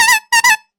Comedy Honk
A bright, silly clown horn honk for comedic emphasis and punchline punctuation
comedy-honk.mp3